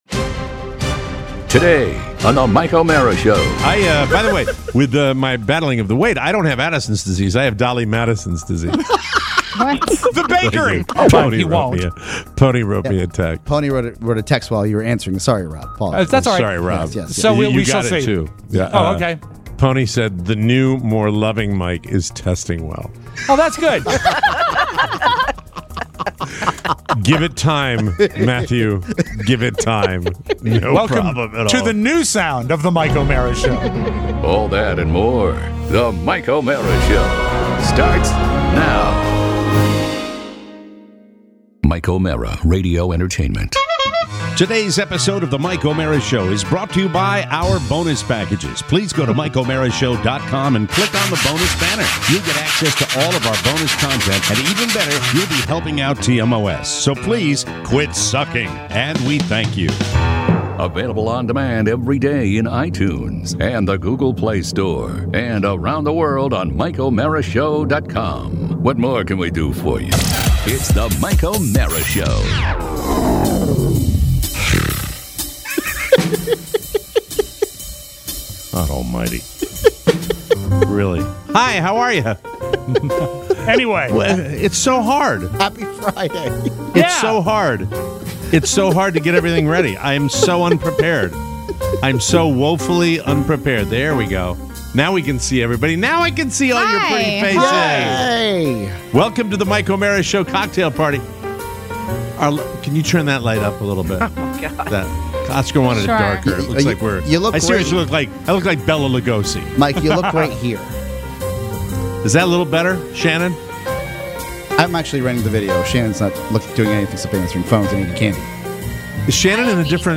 It’s our weekly cocktail hour! With plenty of your calls, mean girls, and a call from a favorite intern.